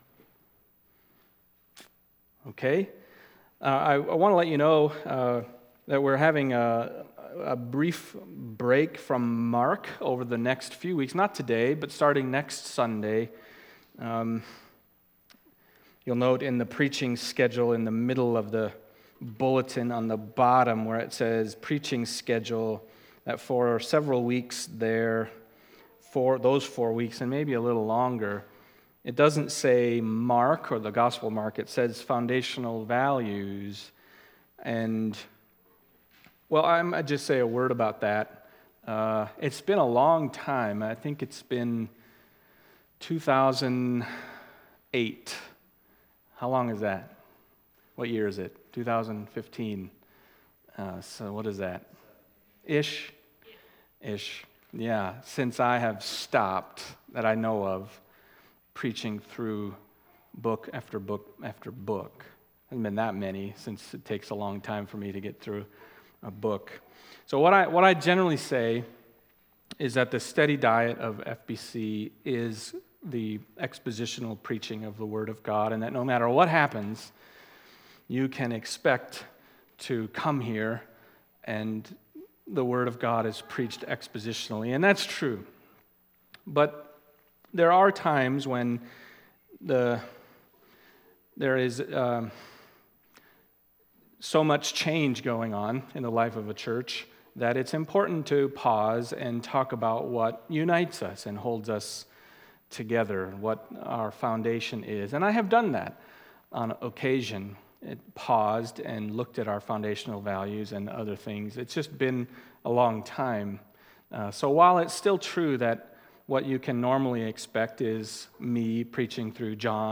Mark Passage: Mark 11:1-11 Service Type: Sunday Morning Mark 11:1-11 « Not the King They Expected The Foundational Values of FBC